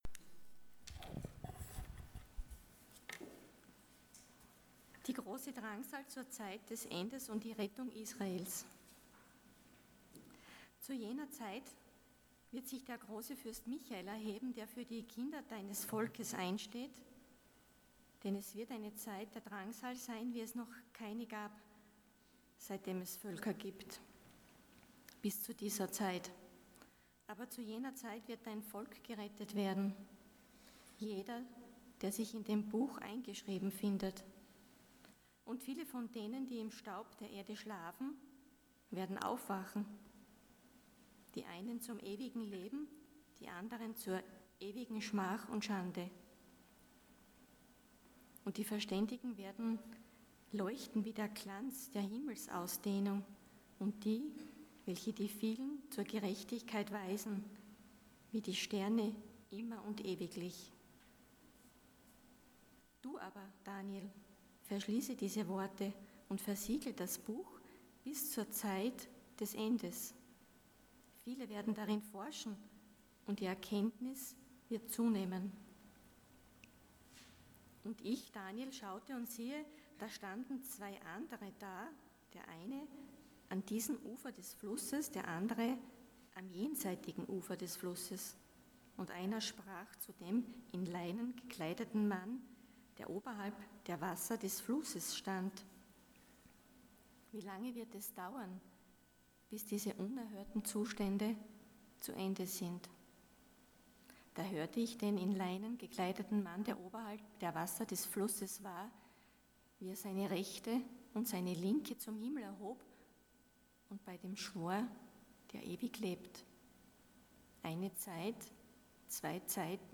Passage: Daniel 12:1-13 Dienstart: Sonntag Morgen